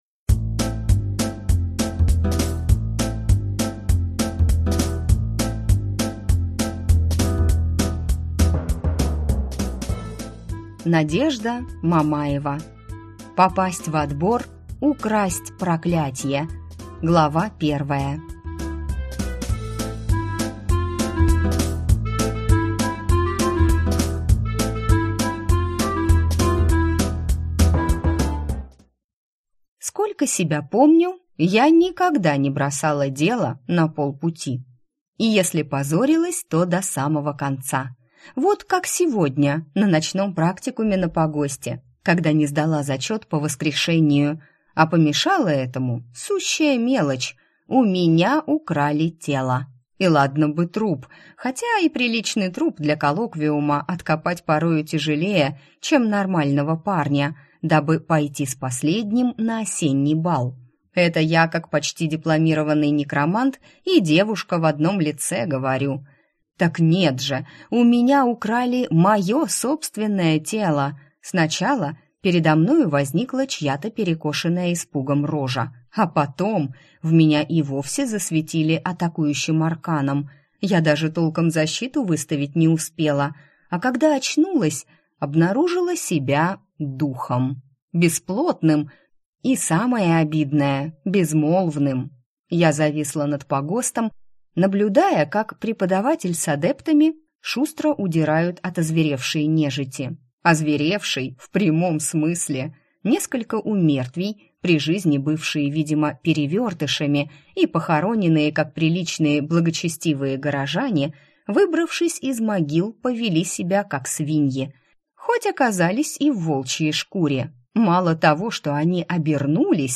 Аудиокнига Попасть в отбор, украсть проклятье | Библиотека аудиокниг